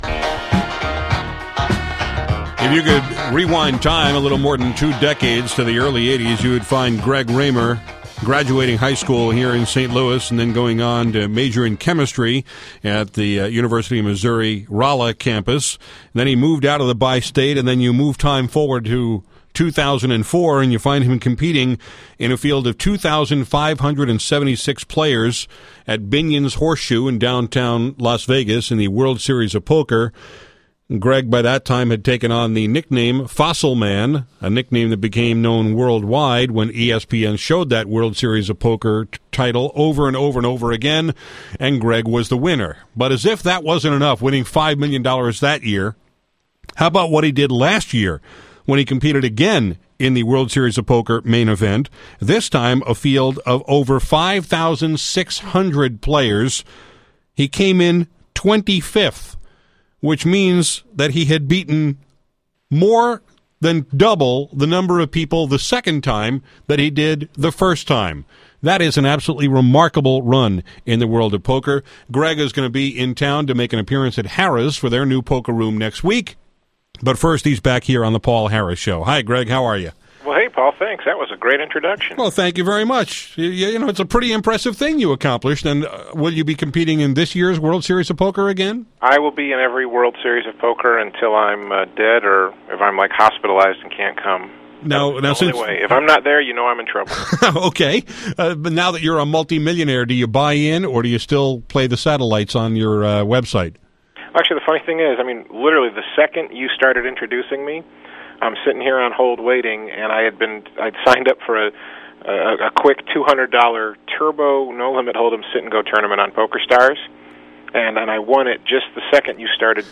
Here’s my conversation with poker champion Greg “Fossilman” Raymer about Congress trying to make online poker illegal (he made some great points about the real agenda there), plus how he chooses the tournaments he plays in, how he did so well in the 2004 and 2005 World Series of Poker, why he won’t play in The Big Game at the Bellagio, and more.